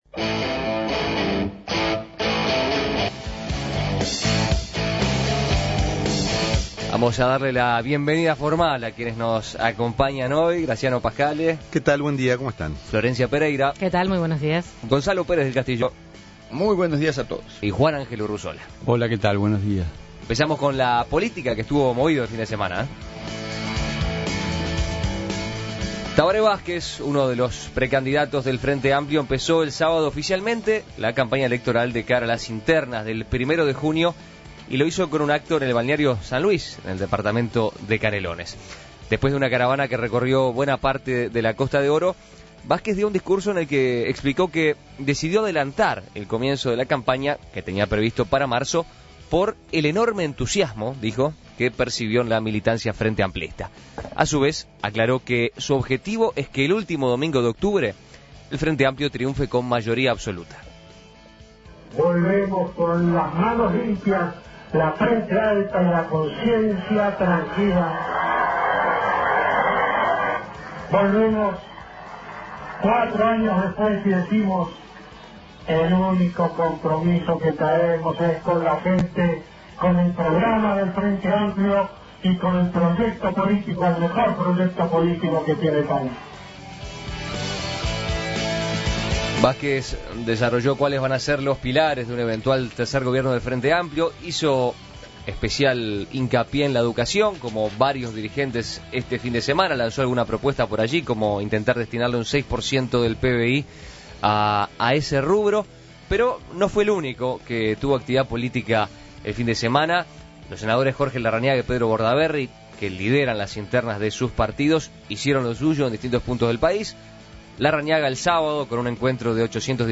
El ex presidente Tabaré Vázquez, uno de los precandidatos del Frente Amplio, arrancó el sábado oficialmente la campaña electoral de cara a las internas del primero de junio con un acto en el balneario San Luis en el departamento de Canelones